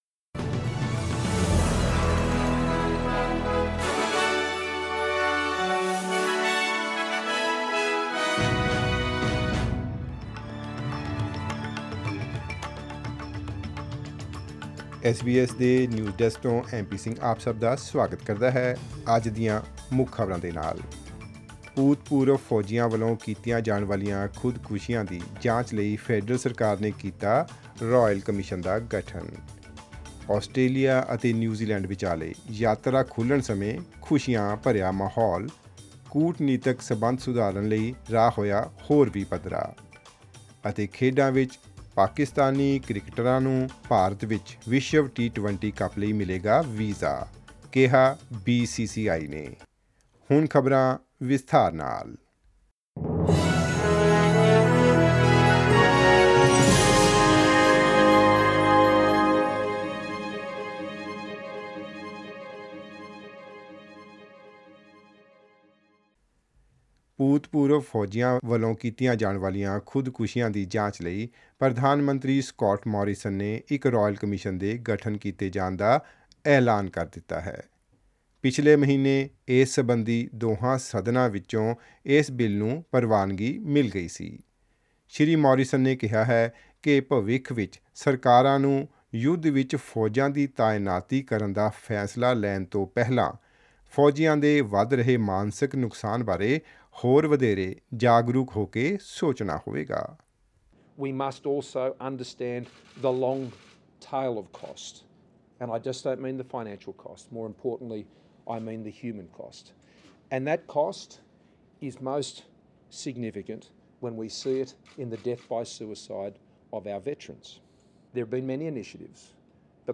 Australian News in Punjabi : Federal Government to establish a royal commission into veteran suicides